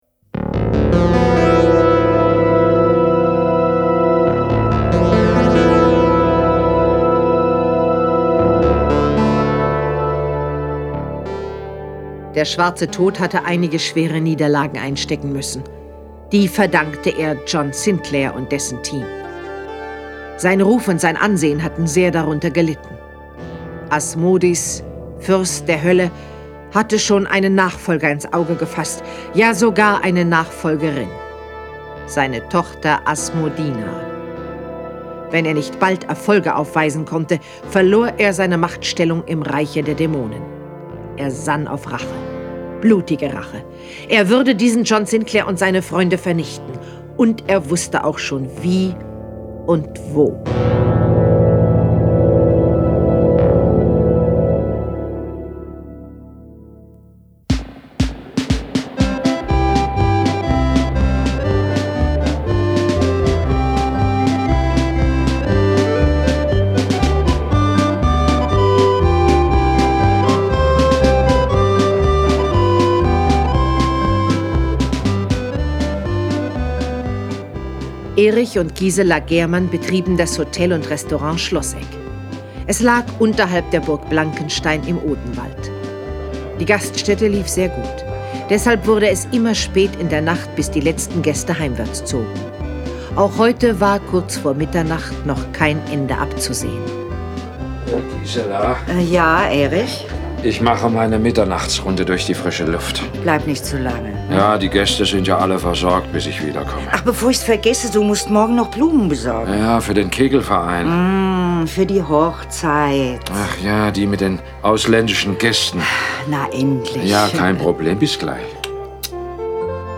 John Sinclair Tonstudio Braun - Folge 13 Der Sensenmann als Hochzeitsgast. Jason Dark (Autor) diverse (Sprecher) Audio-CD 2016 | 1.